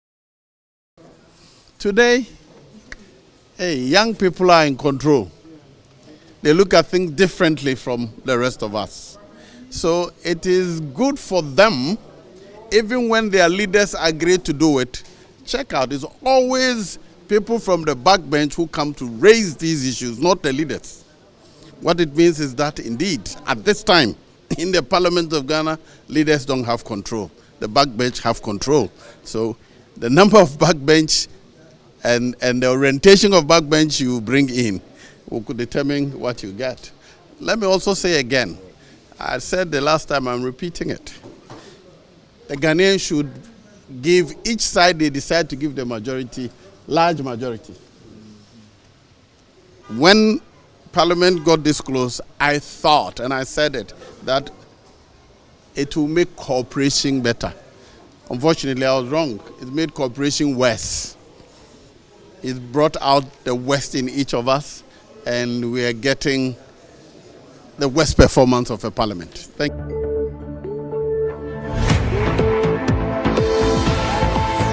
Speaking to journalists in Parliament on Thursday, July 20, the Bekwai lawmaker said “Today, young people are in control, they look at things differently from the rest of us.